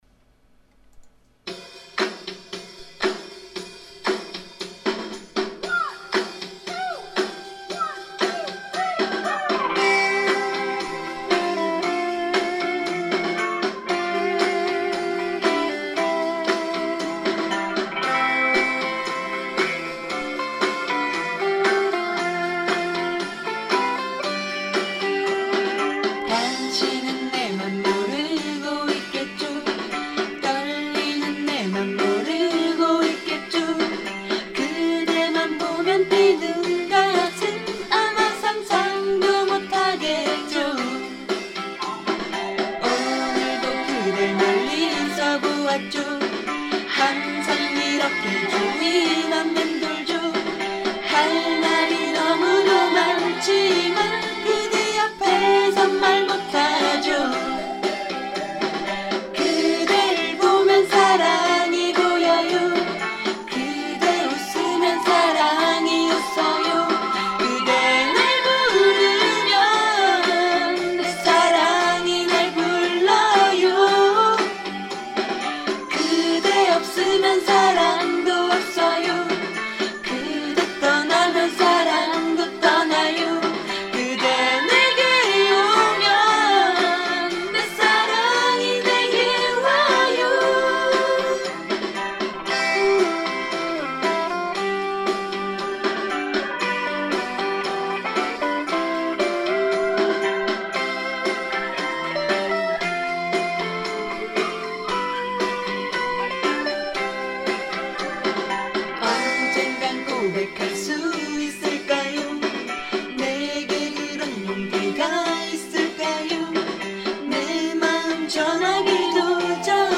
직접 부른 노래를 올리는 곳입니다.